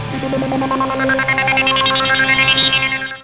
Synth-C.wav